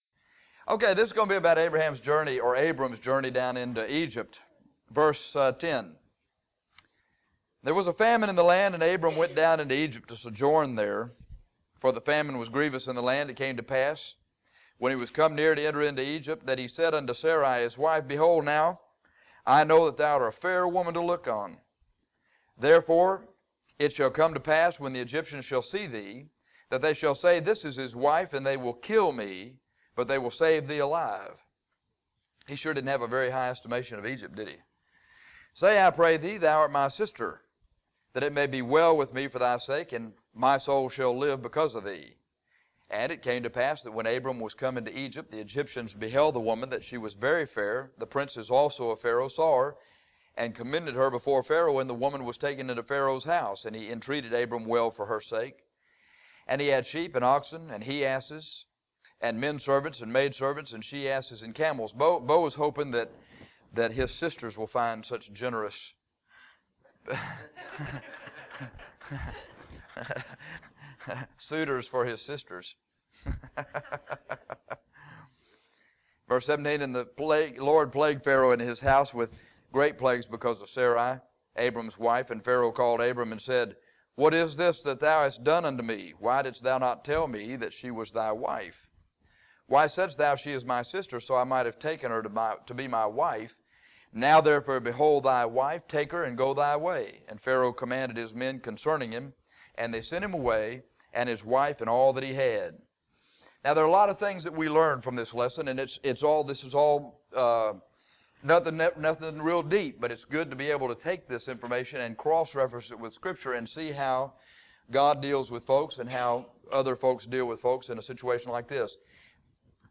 Genesis 12:10-20 Abram Journeys to Egypt CLICK TITLE FOR AUDIO In this lesson, we see how: A famine drove Abram out of Canaan into Egypt; Abram lied out of fear to protect himself; The devil attempted to mess up the “seed;” God blessed Abram in spite of his sin; God plagued Pharaoh to protect Abram […]